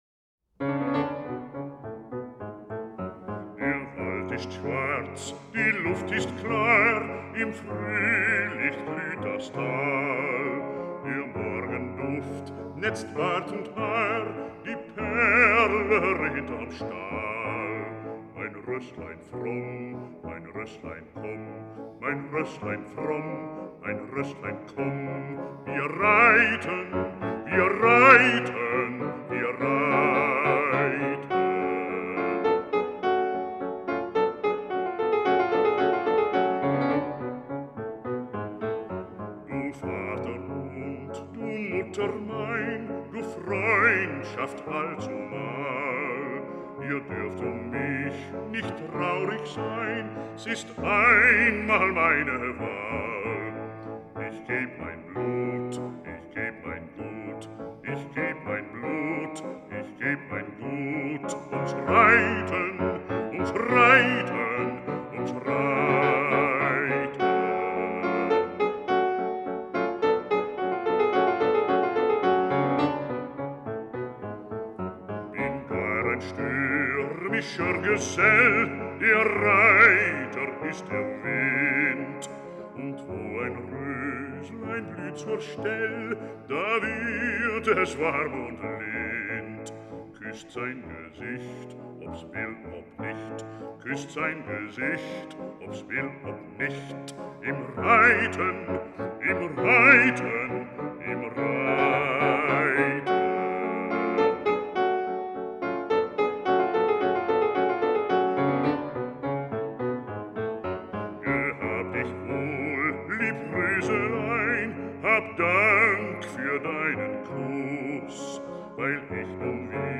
Robert Holl - Bariton,
Piano